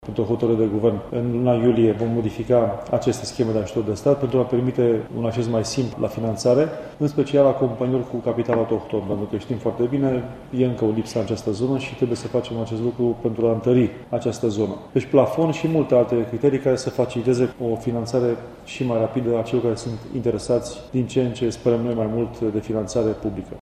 Guvernul reduce, din această lună, plafonul minim de finanţare prin ajutor de stat, de la 10, la 3 milioane de euro, pentru ca şi companiile mai mici să poată depune solicitări, a anunţat, astăzi, ministrul finanţelor, Eugen Teodorovici. El a precizat, într-o conferinţă la Bistriţa, că executivul va aduce mai multe modificări schemelor prin care se acordă sprijin public în special companiilor cu capital autohton.